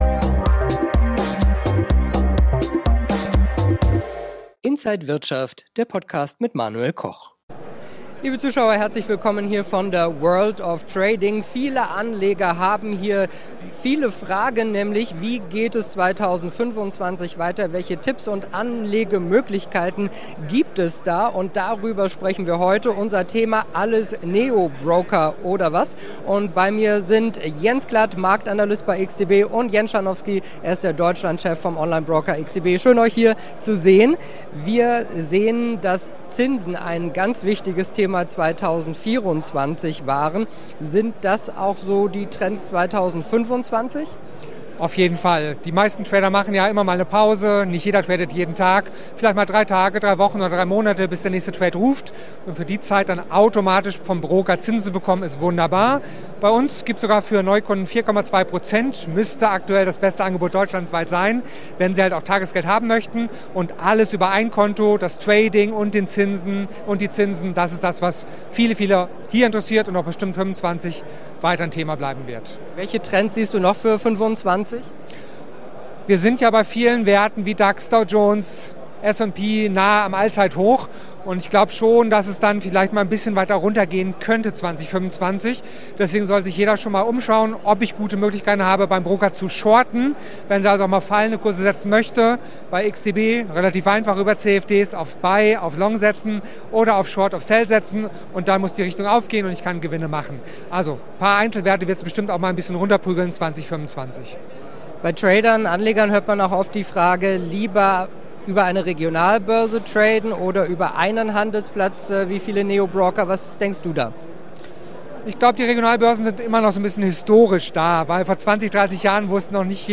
Gespräch
auf der World of Trading